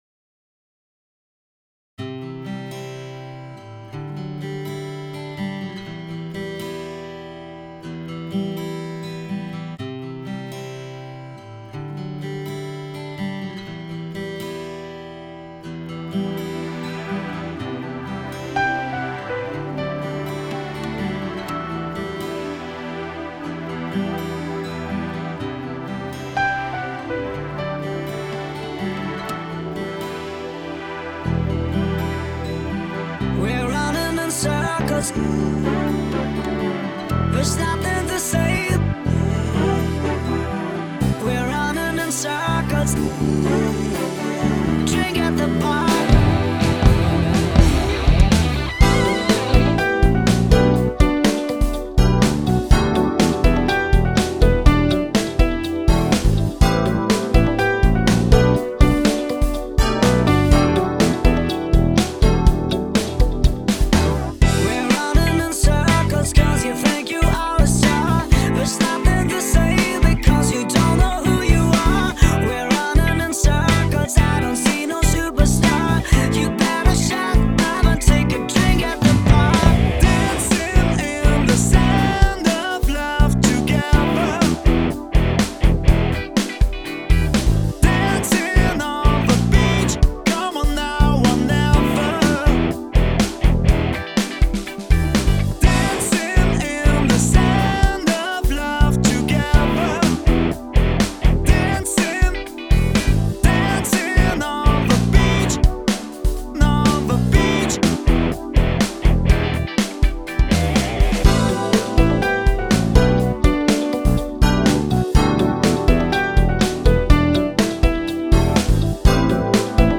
Dancing (naphtha pop